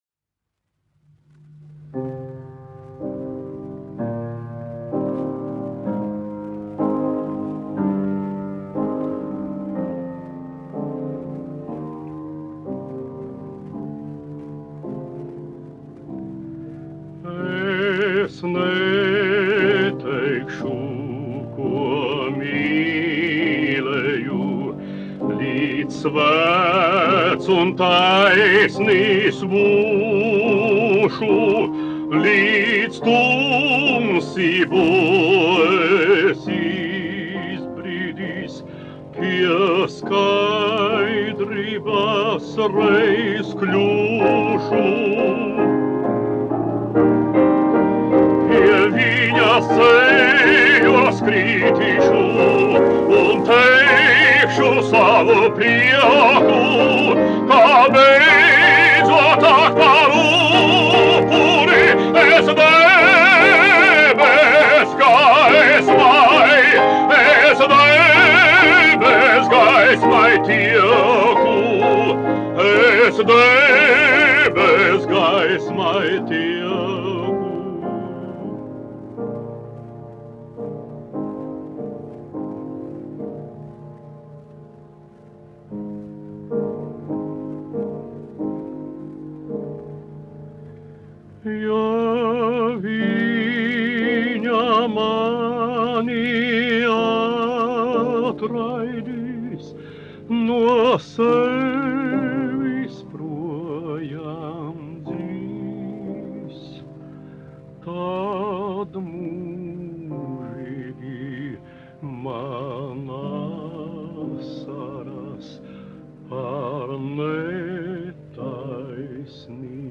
1 skpl. : analogs, 78 apgr/min, mono ; 25 cm
Dziesmas (vidēja balss) ar klavierēm
Latvijas vēsturiskie šellaka skaņuplašu ieraksti (Kolekcija)